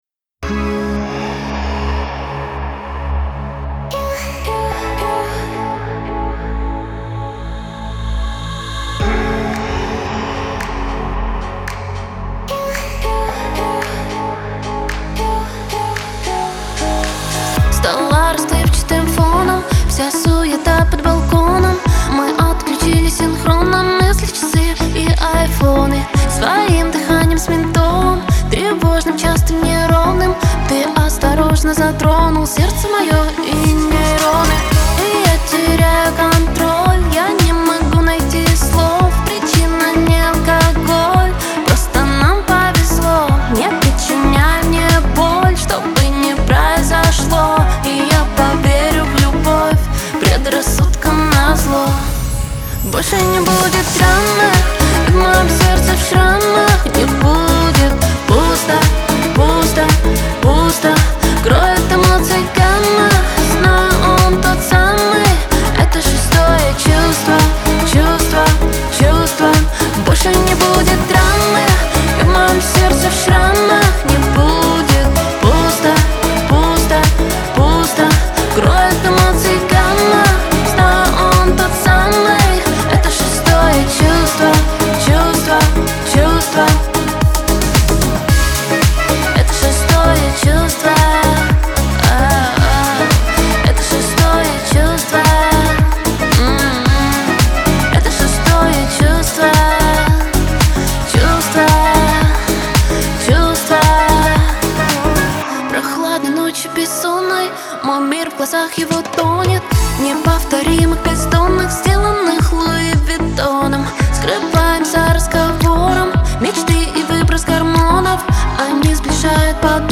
выполненная в жанре поп-рок.